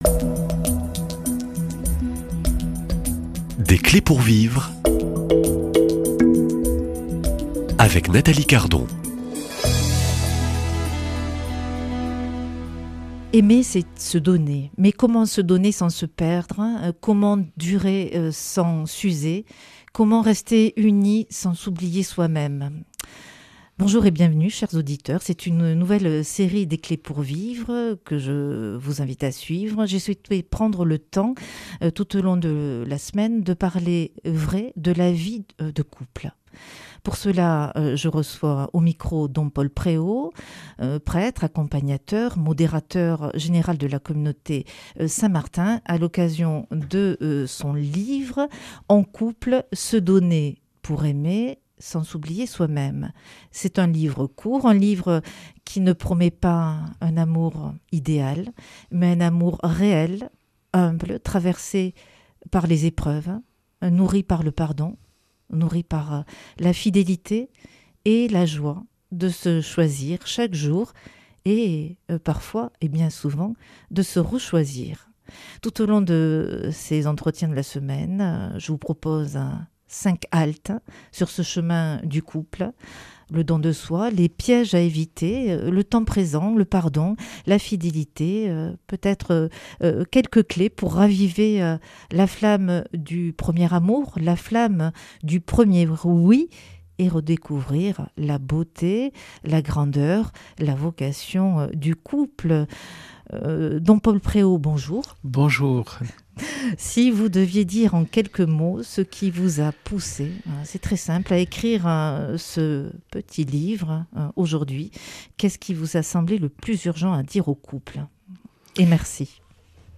Dans ce premier entretien, nous posons les fondations de l’amour conjugal : comment se donner vraiment sans se perdre, comment aimer l’autre en vérité, et faire grandir l’amour dans la durée.